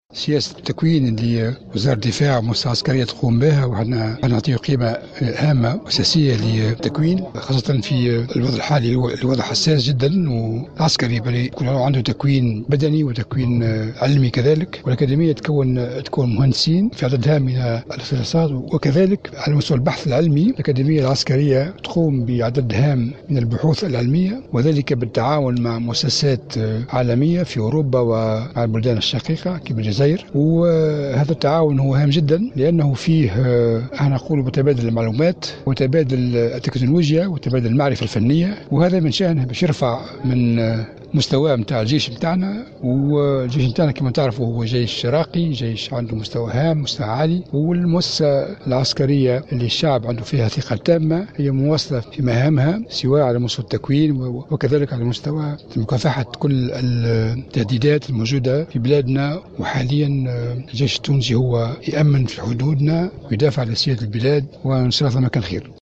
أشرف وزير الدفاع الوطني فرحات الحُرشاني مساء اليوم الأربعاء 27 جويلية 2016 على حفل اختتام السنة التكوينية بالأكاديمية العسكرية بفُندق الجديد .
وأكدّ الوزيرُ في تصريح لمراسلة الجوْهرة "أف أم " المستوى العالي لكفاءات الجيْش الوطني في عدّة اختصاصات مشيرا إلى دوره المهم في حماية أمن البلاد و سيادتها.